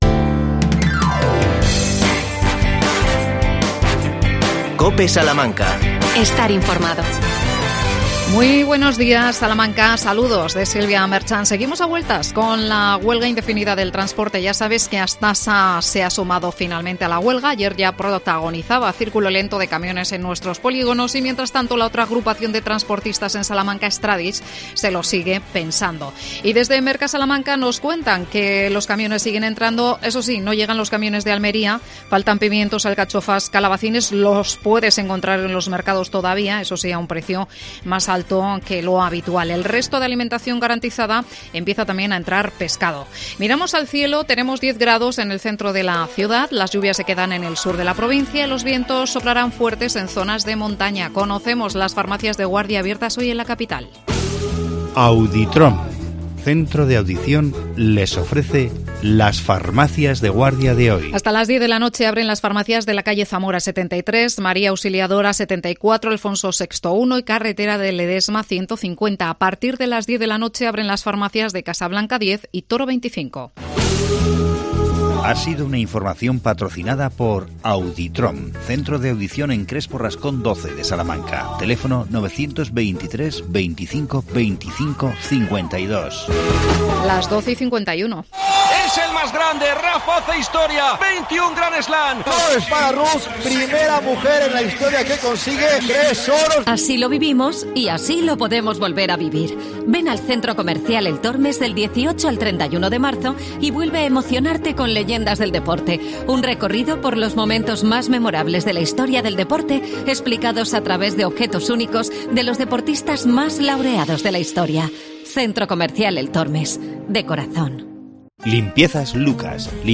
AUDIO: Microespacio Ayuntamiento de Salamanca. Entrevistamos